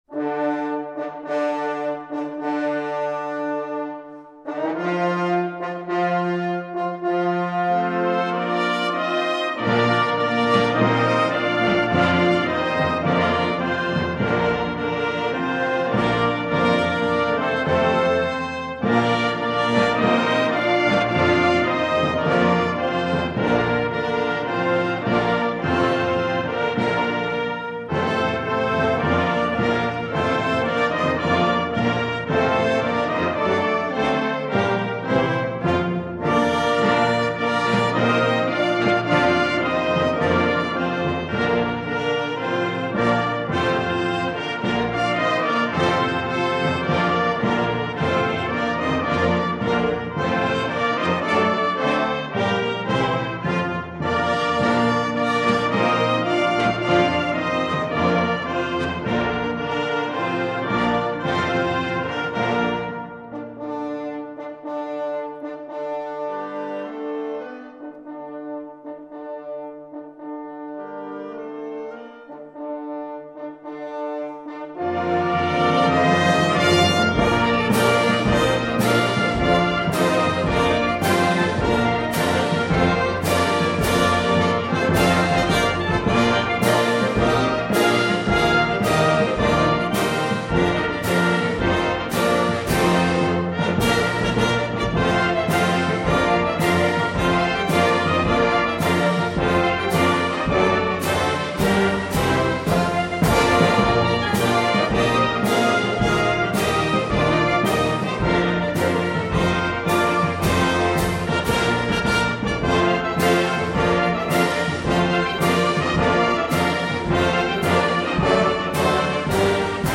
Gelieve bij dit bericht (eveneens in bijlage) het juiste muziekfragment te vinden dat donderdag gespeeld werd.